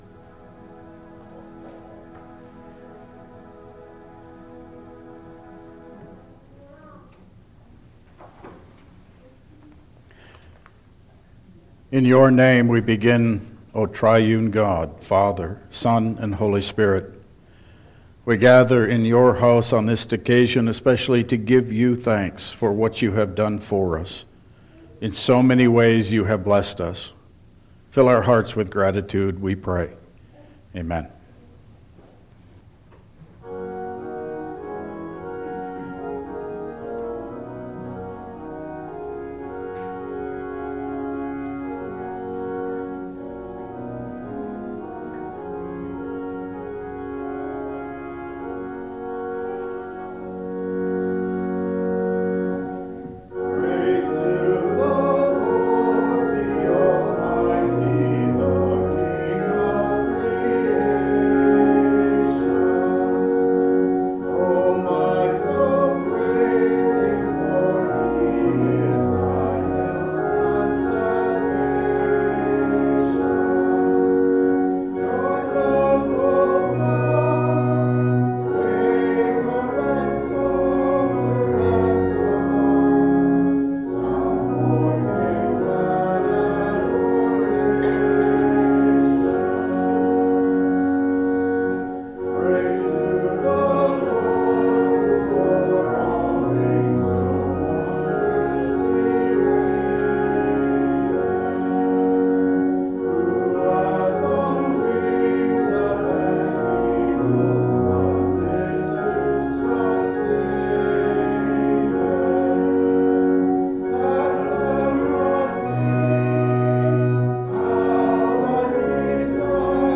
Download Files Printed Sermon and Bulletin
Service Type: Thanksgiving Service